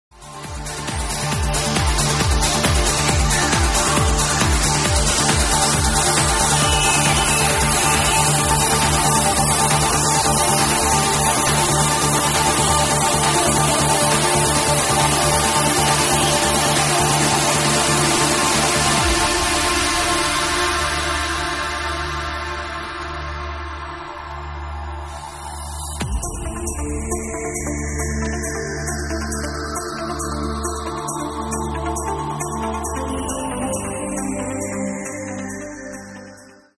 it has no vocals.